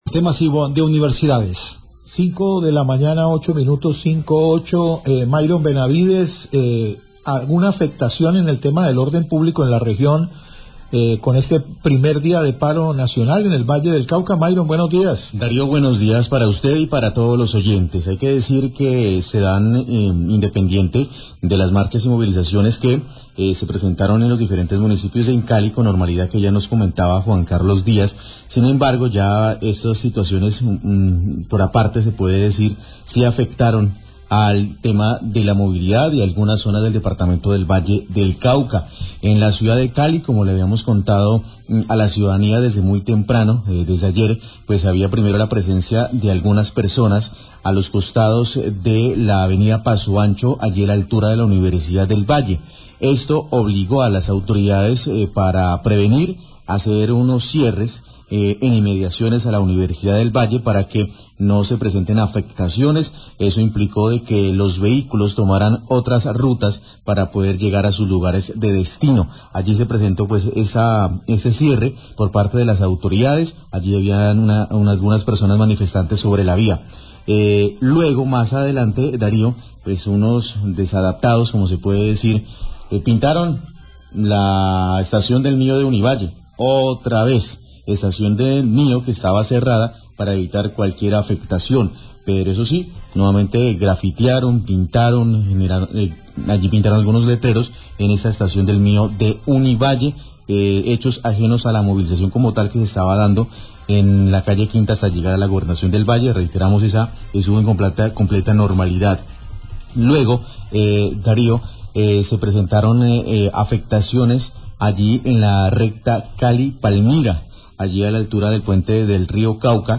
Radio
Periodistas entregan informe de diferentes incidentes del primer día de paro del mes de mayo en la ciudad de Cali. Reportan bloqueos de vía y daños.